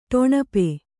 ♪ ṭoṇape